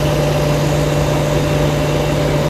Dehumidifier | Sneak On The Lot
Dehumidifier Loop Condenser Running